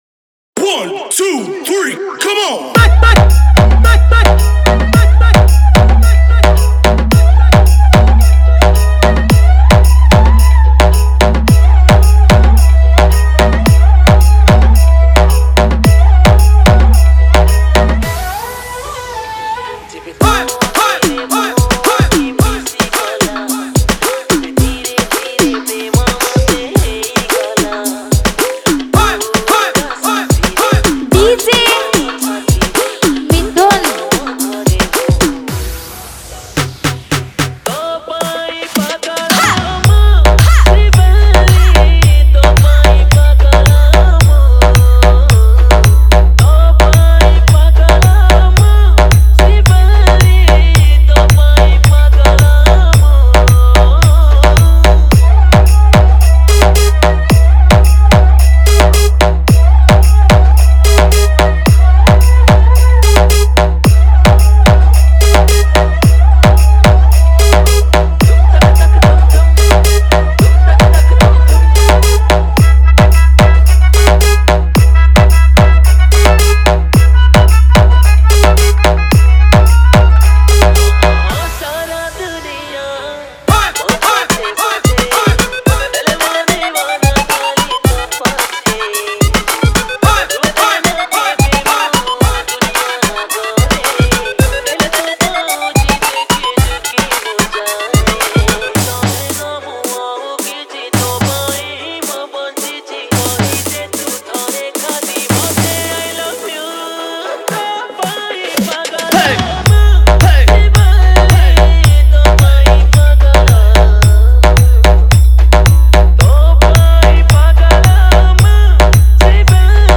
Odia Cover Song Dance Remix 2022